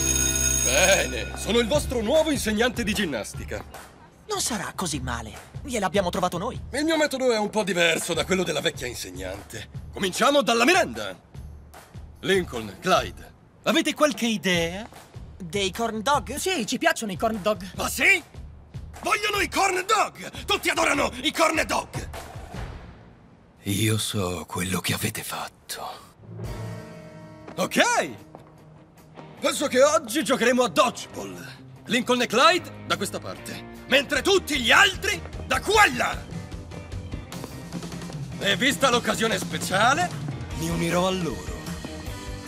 voce